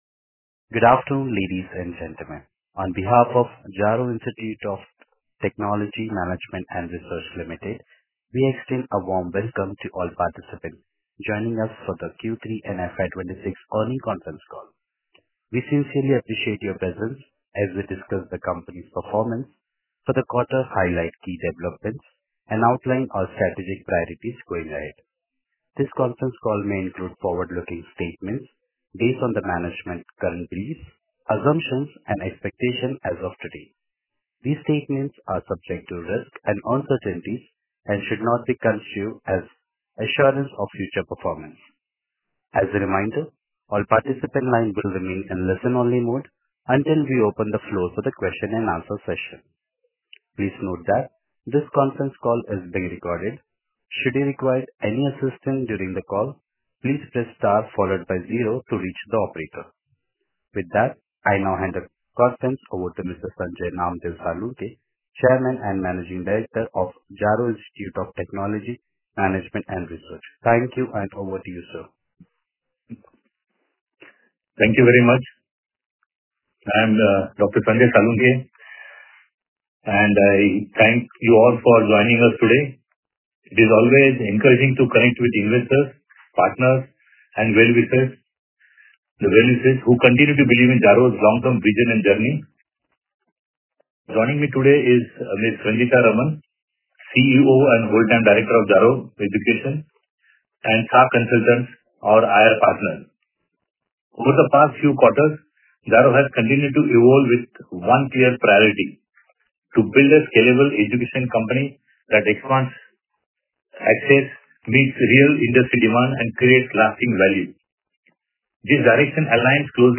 Earnings-Conference-Call_Transcript_Q3-FY26.mp3